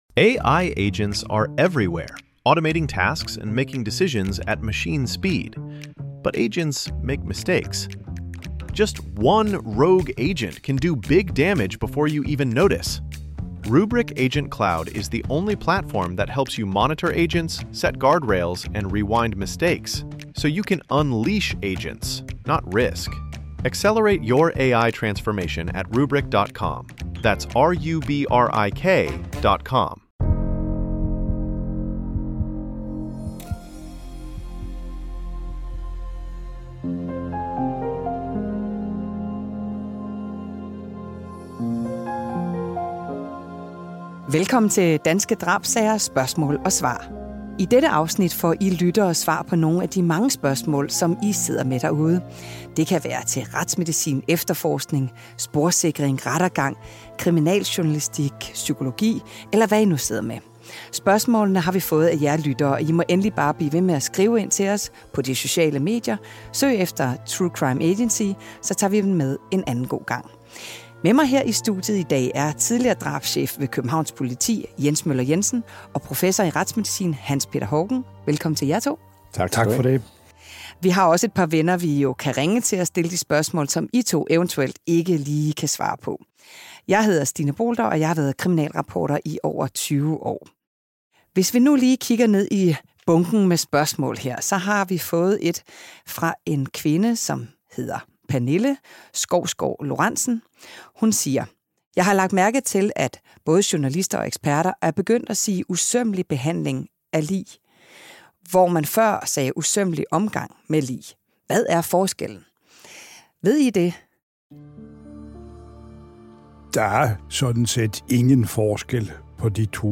Professor i retsmedicin